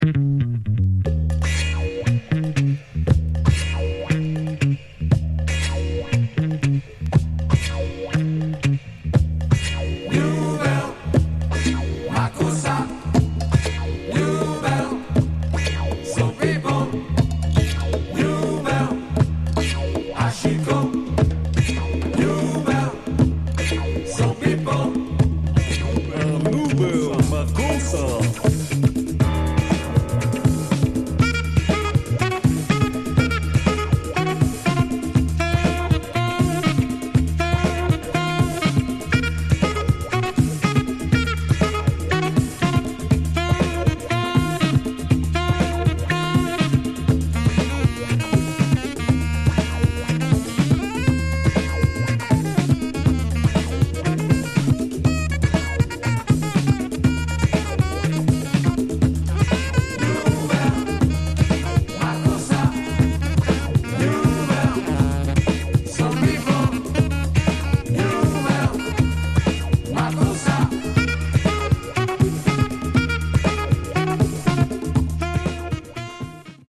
A wicked little comp. of obscure Afro-Disco nuggets.
Funky Highlife